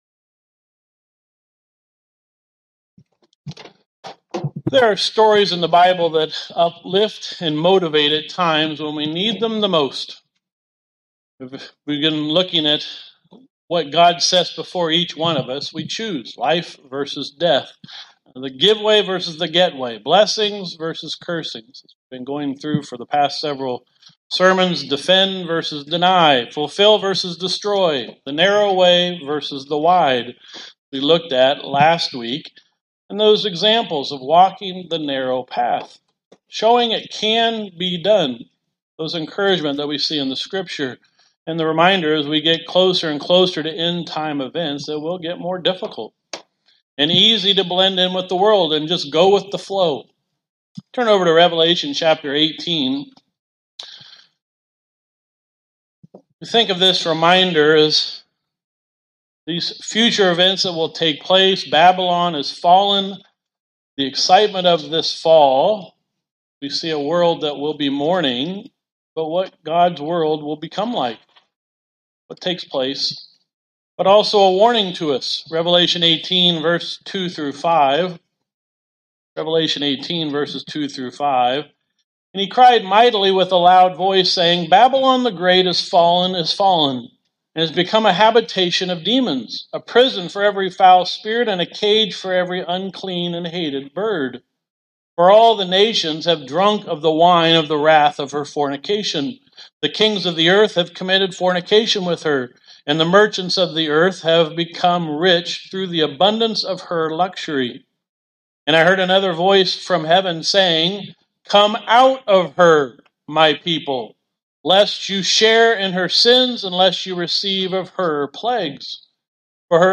Sermons
Given in Elkhart, IN Northwest Indiana